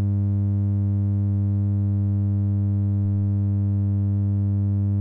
OGG hluk + English: Made it myself with and adaptor and an audio input cable, 2006
50Hz.ogg